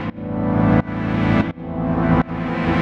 Index of /musicradar/sidechained-samples/170bpm
GnS_Pad-MiscA1:2_170-A.wav